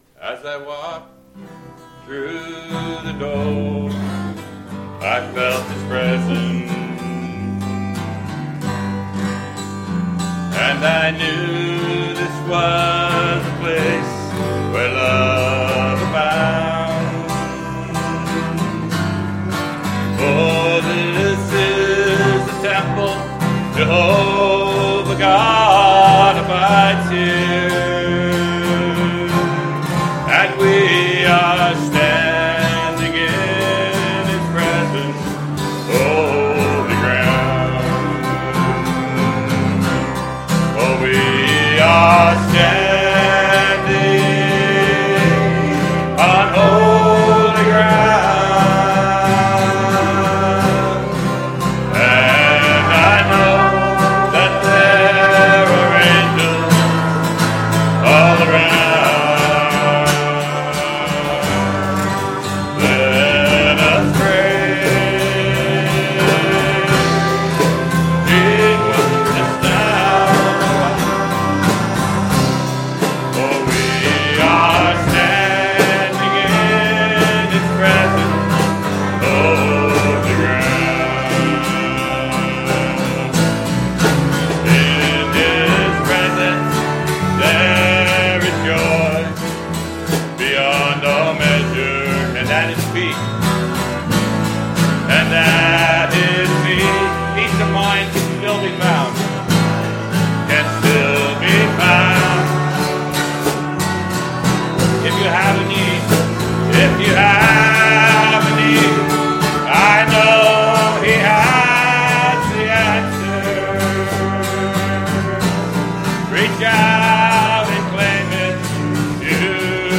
Upsala Conv Mar 2026
Fri AM-Praise